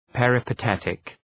{,perəpə’tetık}